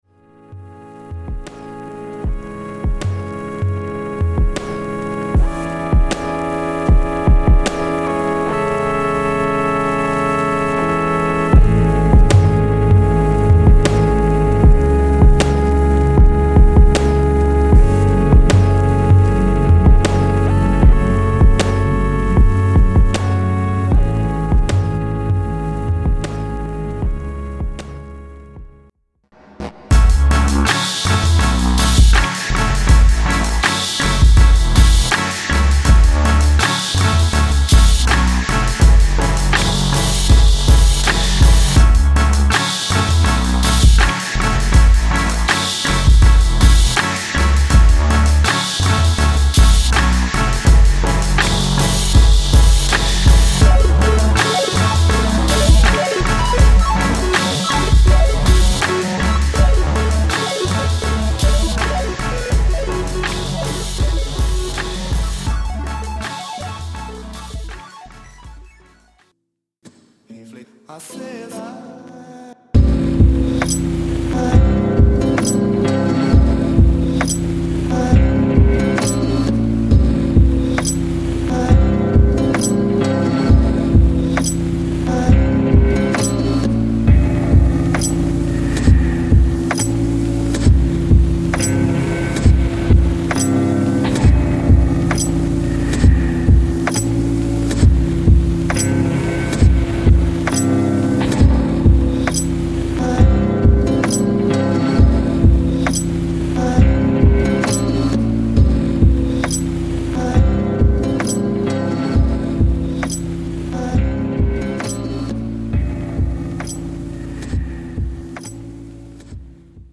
restrained and thoughtful tracks